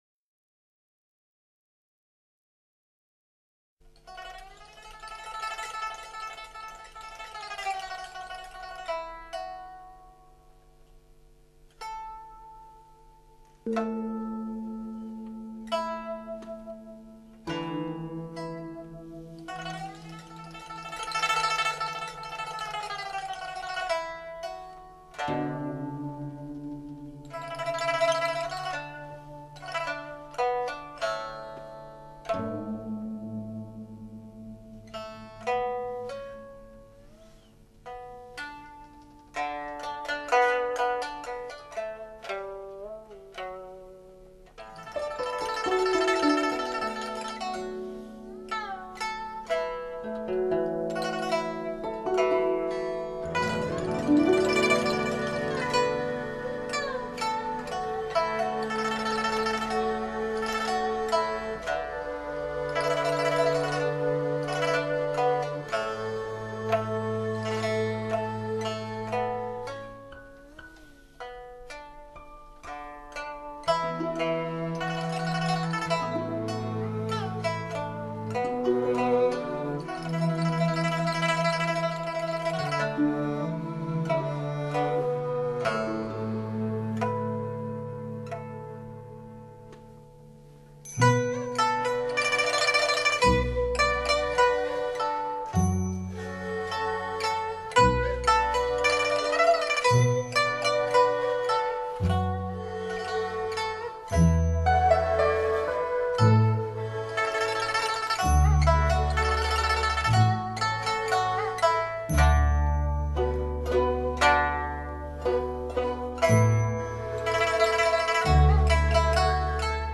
大多数的弹拨乐器都具有音色明亮、清脆的特点，其弹奏方法
迂回婉转的音律，卓越深厚的演奏、憾人肺腑的乐魂、